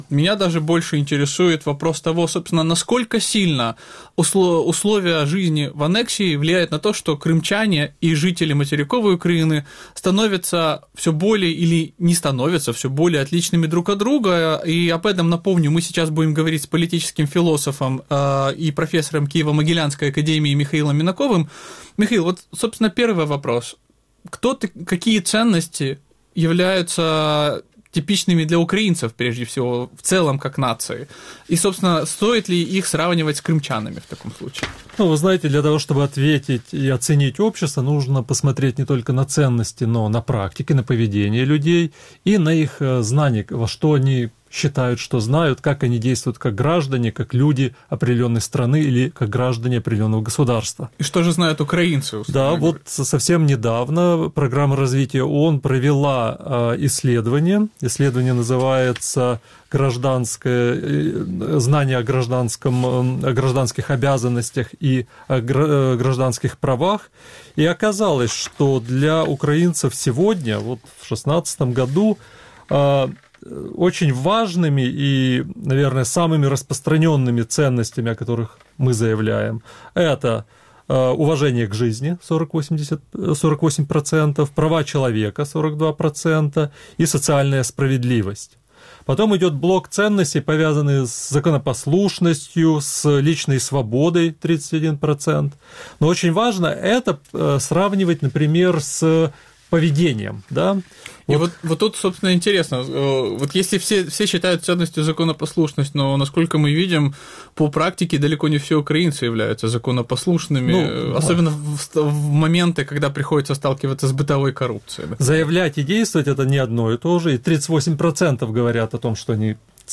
Вранці в ефірі Радіо Крим.Реалії говорять про перспективи реінтеграції кримчан, які з 2014 року живуть в умовах анексії Криму Росією. Як менталітет кримчан змінюється в умовах життя на анексованій території? Чи відбувається соціальний і ментальний розрив між Кримом і українським материком?